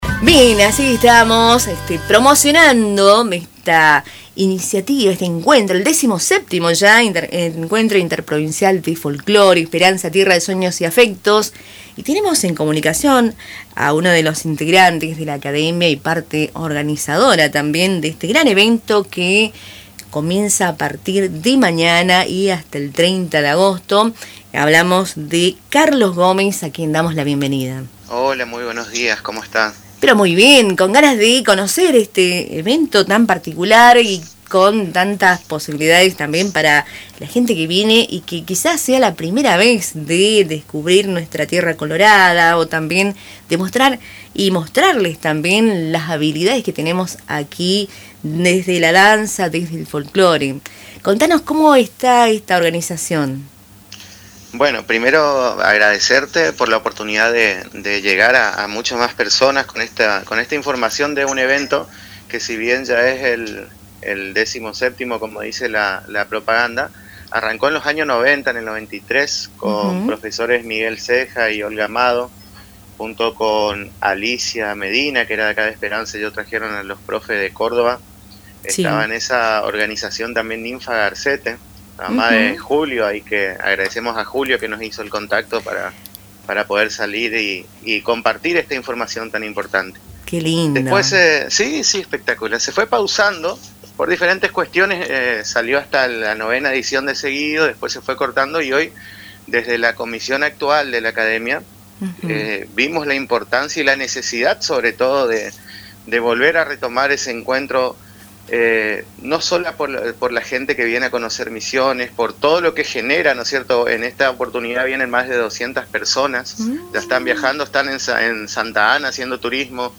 dialogó con Radio Tupambaé y destacó la importancia cultural y turística de la iniciativa.